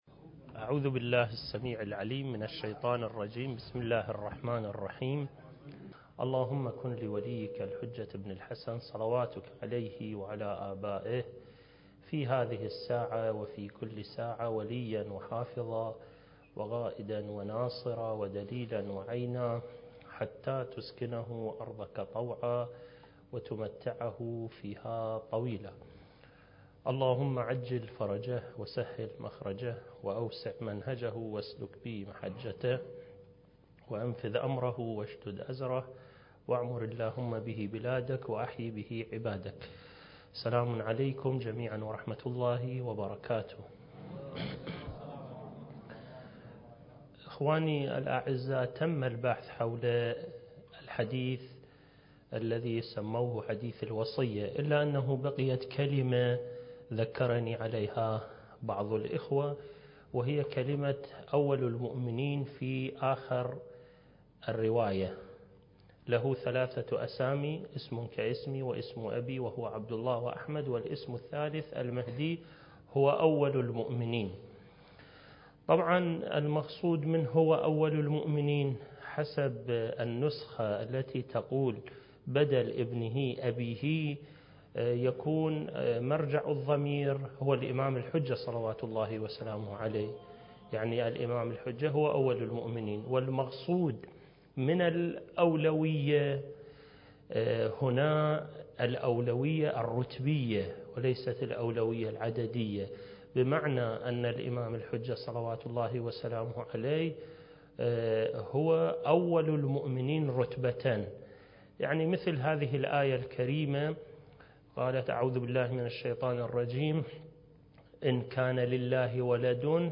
المكان: مؤسسة الإمام الحسن المجتبى (عليه السلام) - النجف الأشرف دورة منهجية في القضايا المهدوية (رد على أدعياء المهدوية) (7) التاريخ: 1443 للهجرة